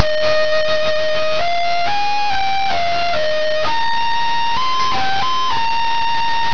Solution:  You make the files by dividing and then multiplying by 2 (7 bits), dividing and then multiplying by 4 (6 bits), etc., all the way up to dividing and then multiplying by 128, which uses only 1 bit to record each amplitude.
flute3.wav